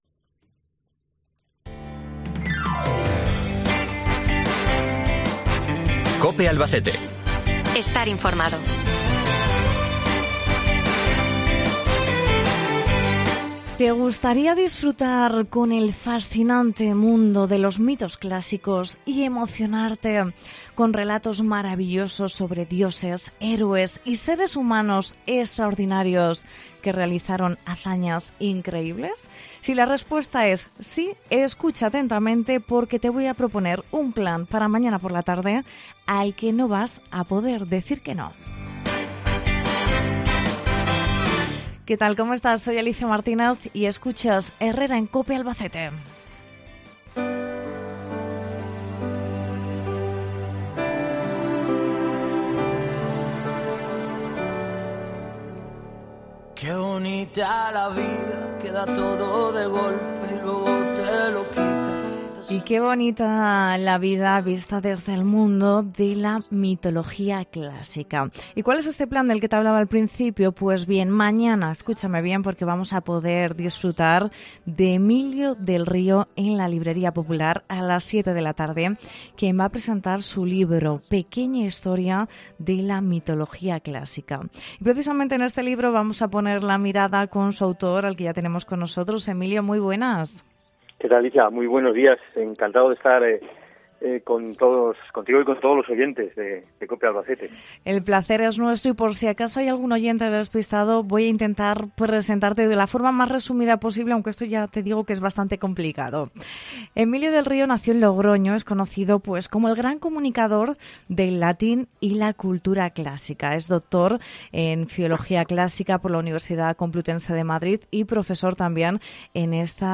Entrevista Emilio del Río